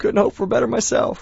gutterball-3/Gutterball 3/Commentators/Bill/b_hopeforbetter.wav at 58b02fa2507e2148bfc533fad7df1f1630ef9d9b